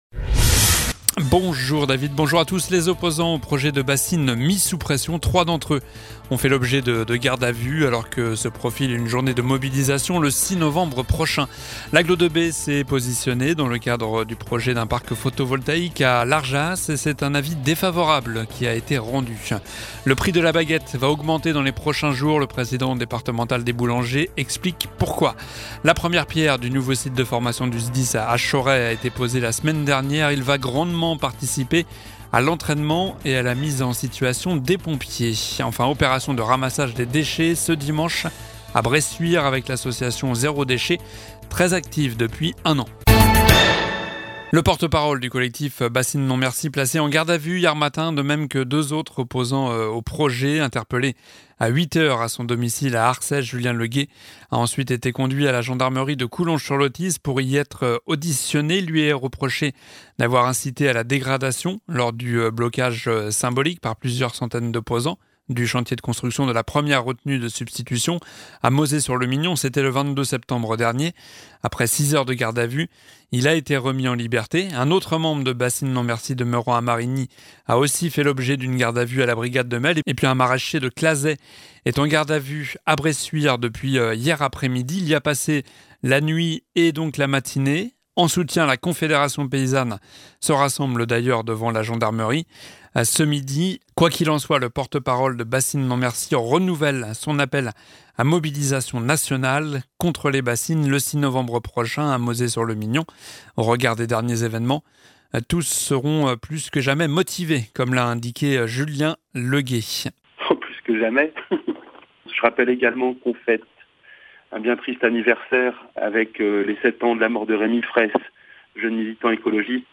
Journal du jeudi 28 octobre